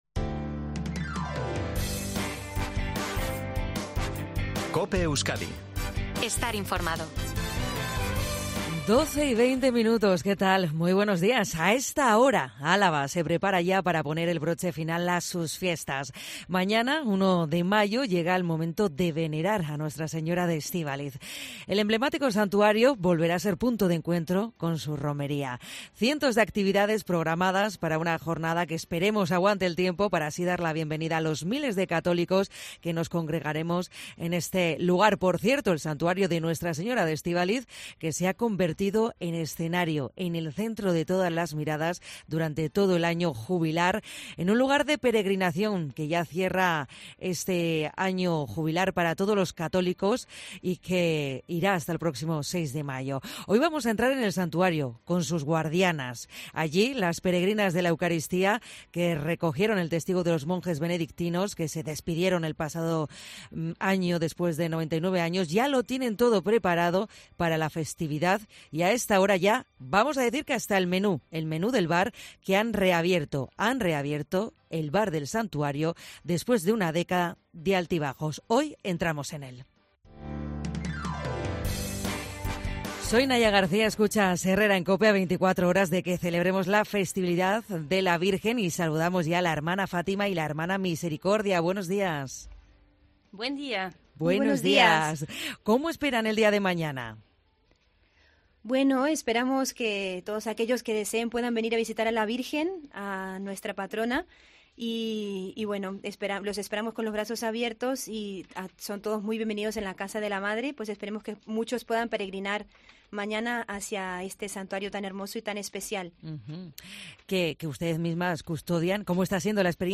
desde los estudios de COPE Euskadi